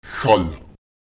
Lautsprecher hal [Cal] atmen